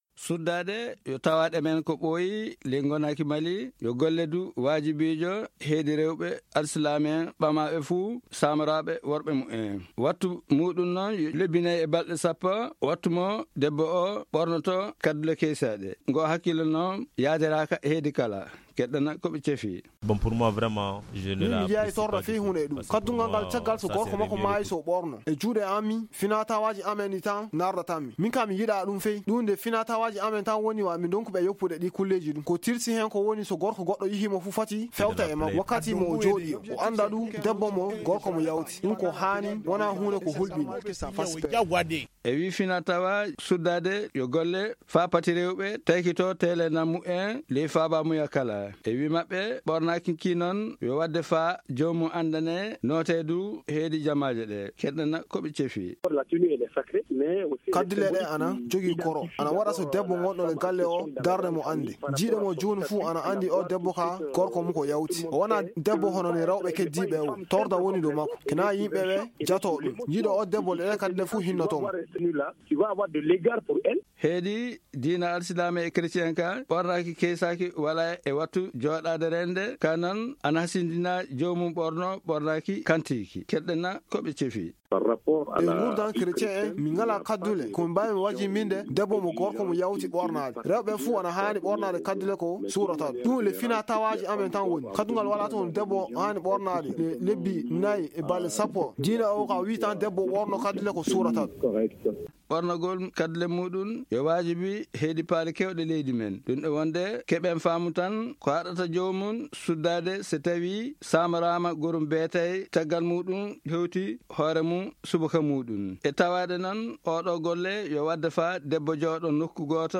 Magazine en sonrhai: Télécharger